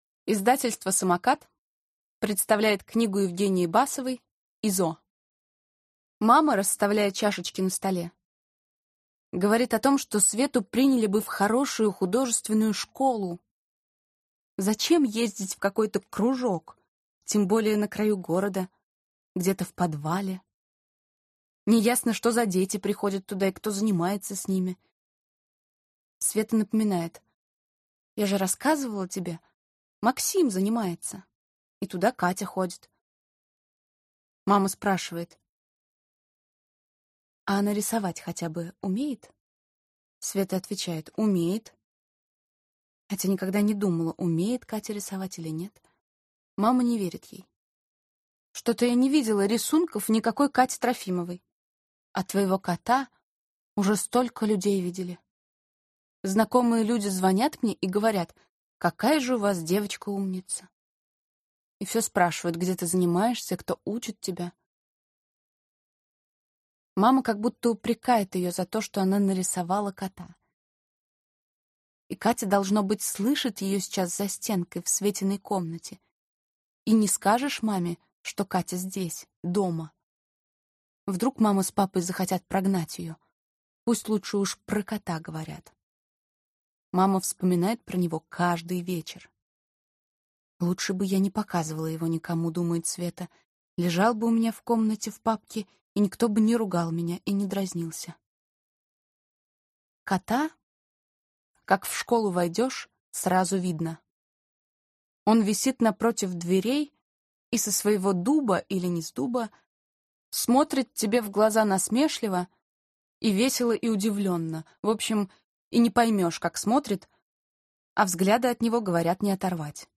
Аудиокнига Изо | Библиотека аудиокниг